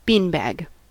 Uttal
Alternativa stavningar bean bag bean-bag Synonymer beanbag chair hackey sack Uttal US Okänd accent: IPA : /ˈbiːnbæɡ/ Ordet hittades på dessa språk: engelska Ingen översättning hittades i den valda målspråket.